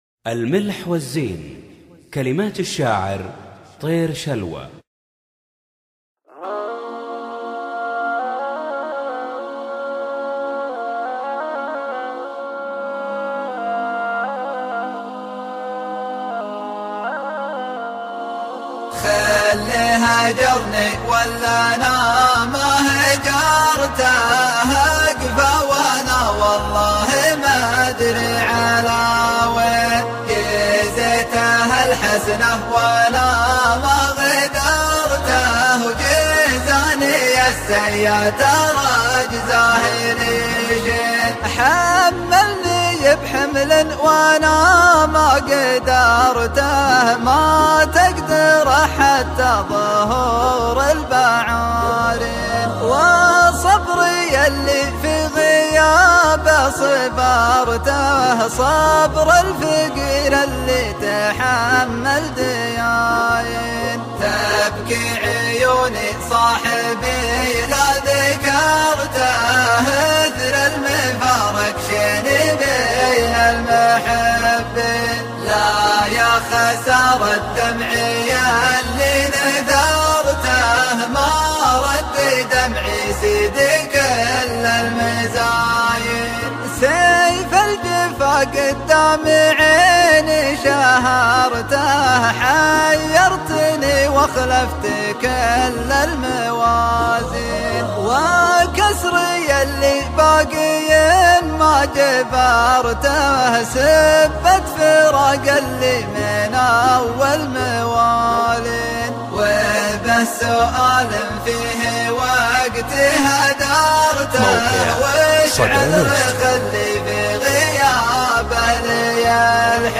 شيلة
مسرعة